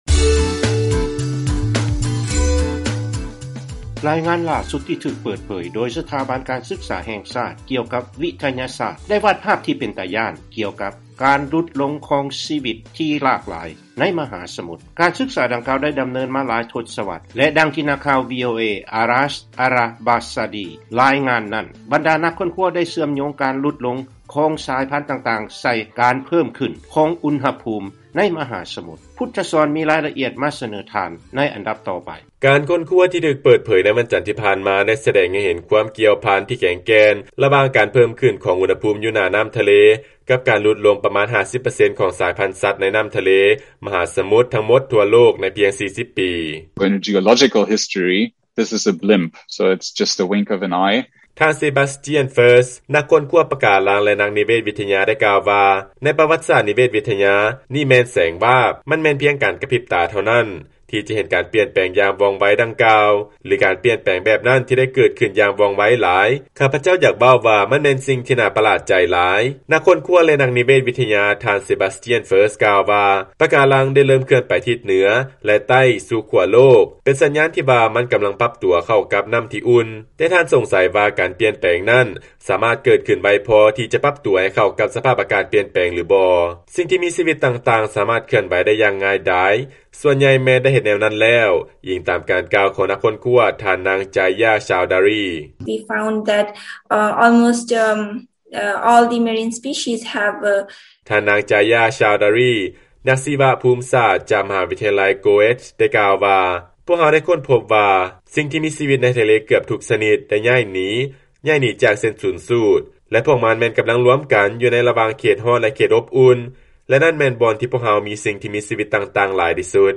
ຟັງລາຍງານ ລາຍງານໄດ້ຄົ້ນພົບວ່າ ຄວາມຫຼາກຫຼາຍ ທາງຊີວະພາບໃຕ້ນໍ້າ ຢູ່ໃກ້ກັບເສັ້ນສູນສູດ ແມ່ນກຳລັງຫຼຸດລົງ